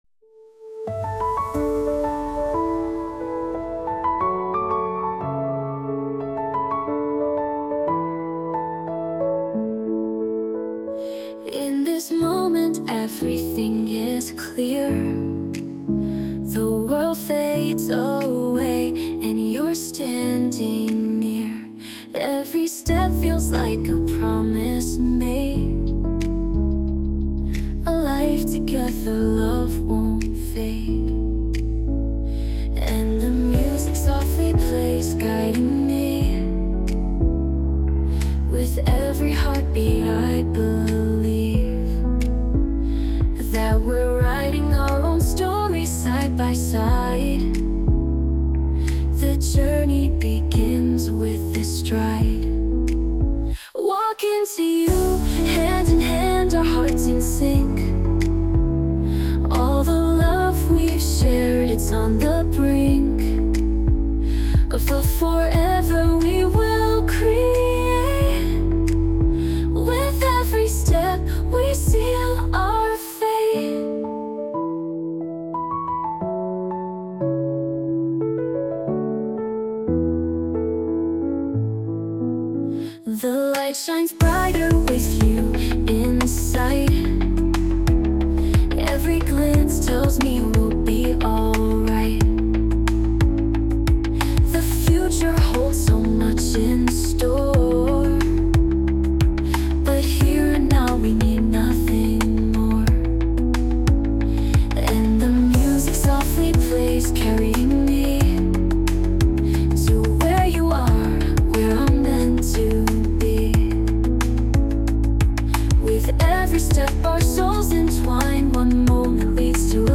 洋楽女性ボーカル著作権フリーBGM ボーカル
女性ボーカル（洋楽・英語）曲です。
結婚式向け・ウェディング向けというコンセプトの音楽チャンネルですので、今回はがっつりウェディンソング✨